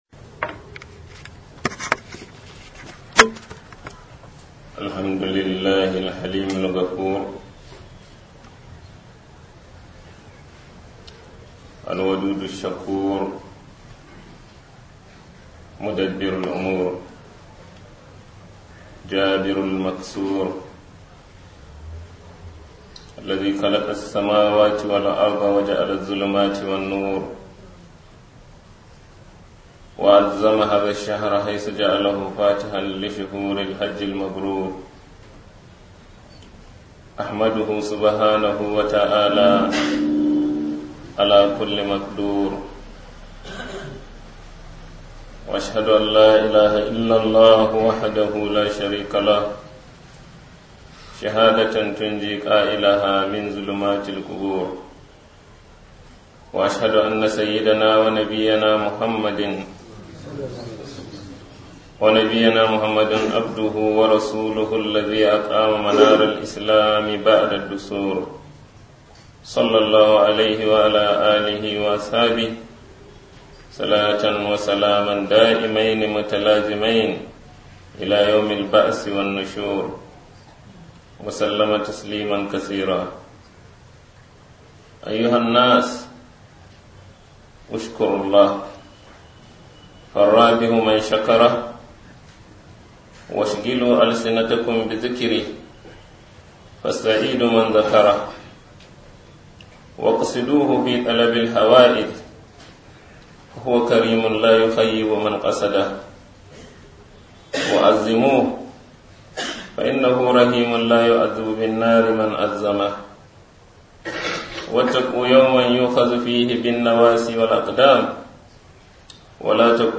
kutba 12-07-19.MP3